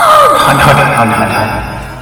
Cries
HAUNTER.mp3